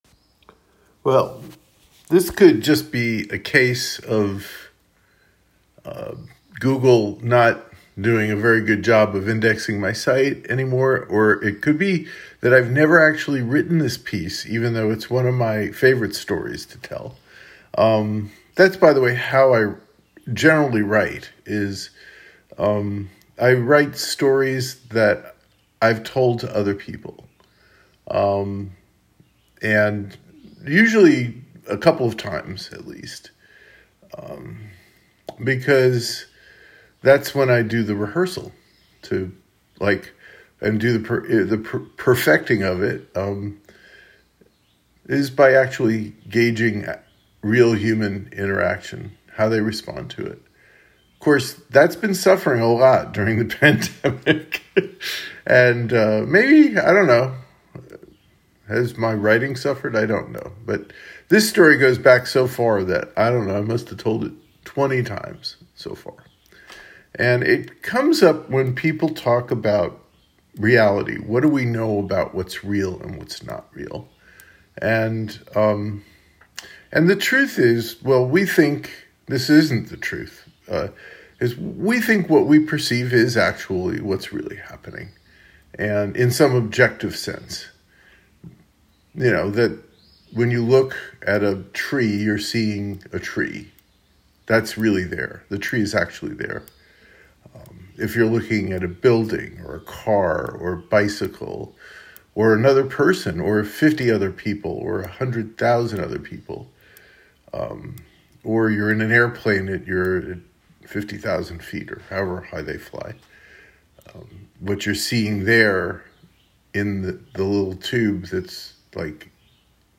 theTreeInTheOutdoorAustinBar.m4a